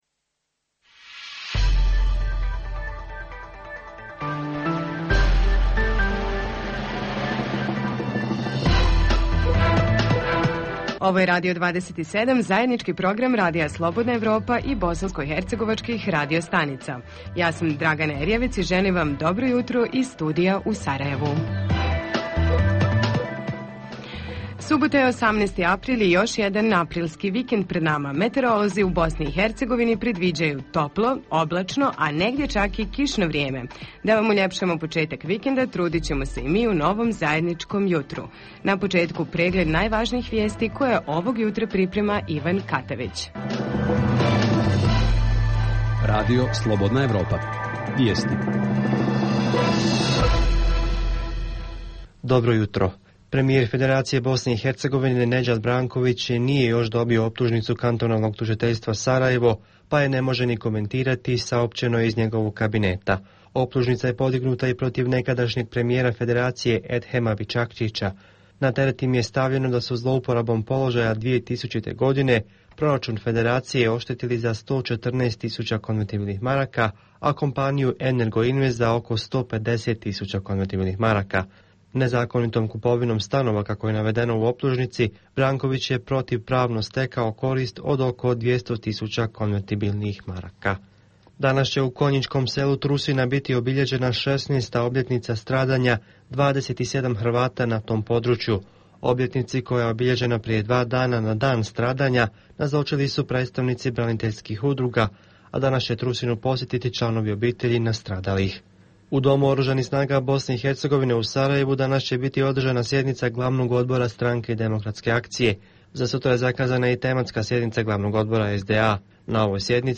Jutarnji program za BiH koji se emituje uživo. U ovoj emisiji možete čuti savjete psihologa, baštovana, travara, te koju knjigu predlažemo da pročitate.
Redovni sadržaji jutarnjeg programa za BiH su i vijesti i muzika.